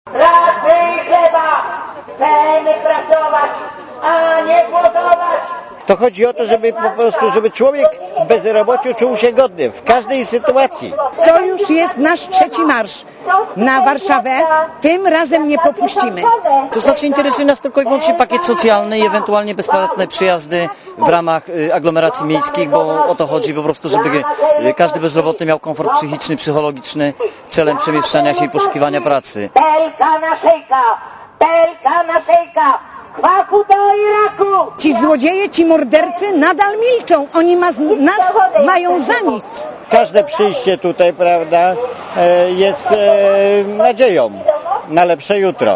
Komentarz audio
bezrobotnimarsz.mp3